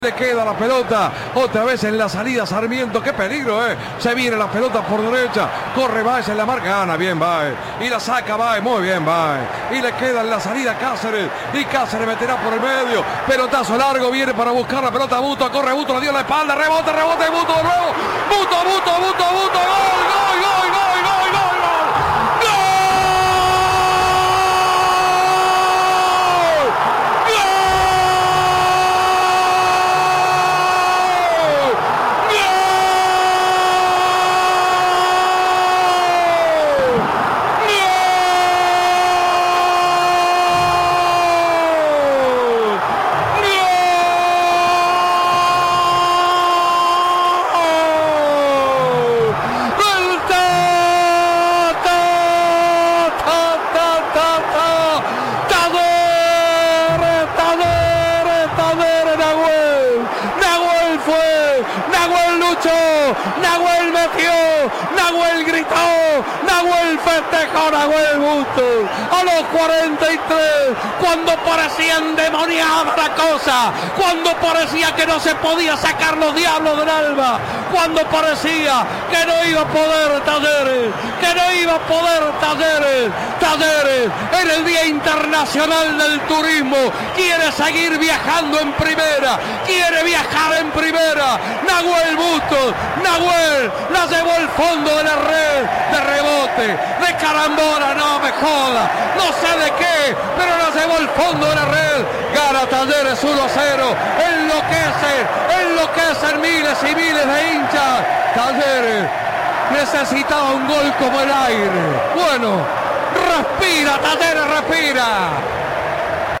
El emotivo relato del gol de Nahuel Bustos a Sarmiento
El relato de ese tanto, cargado de emoción y con el rugido del Kempes de fondo, quedará en la memoria de los hinchas.